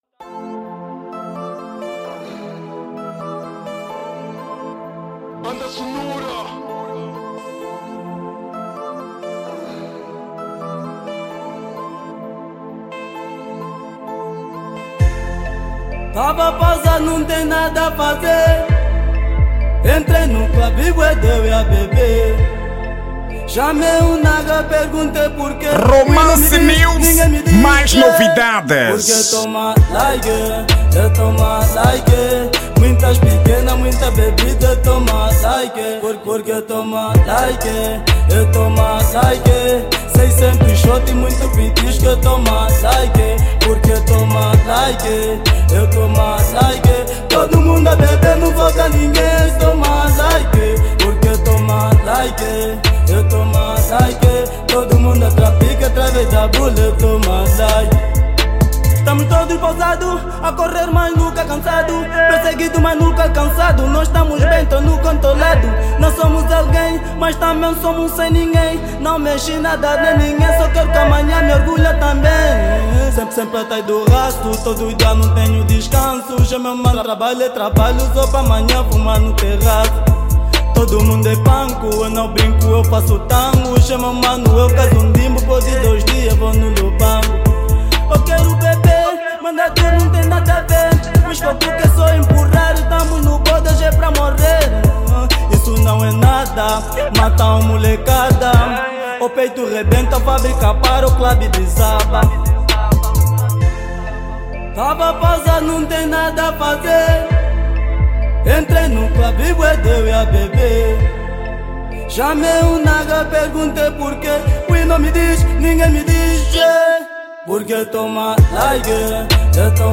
Estilo: Rap Trap